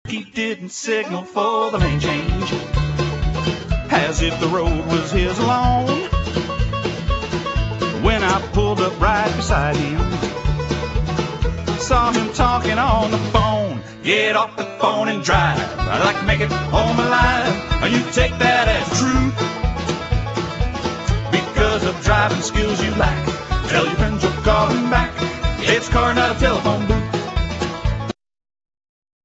(COMEDY SONGS)